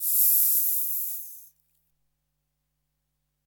Siseo de serpiente
serpiente
siseo
Sonidos: Animales